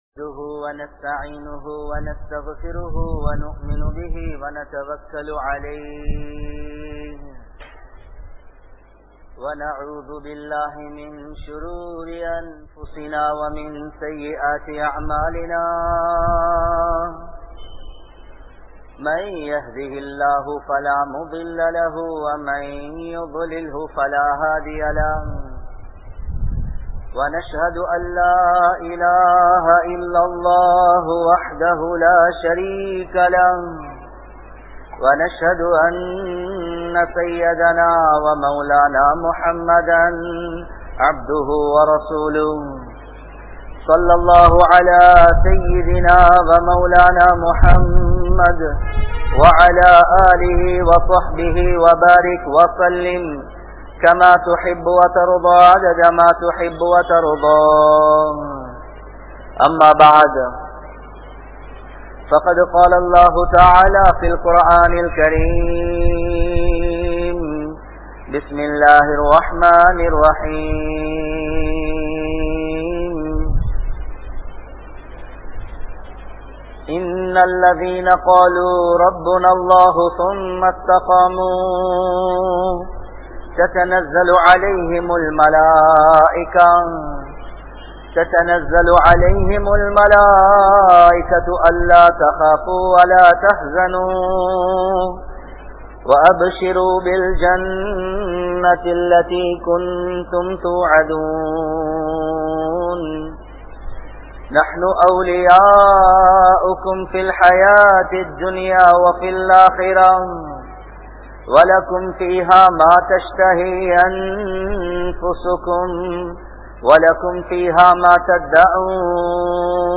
Ganniyam Veanduma? (கண்ணியம் வேண்டுமா?) | Audio Bayans | All Ceylon Muslim Youth Community | Addalaichenai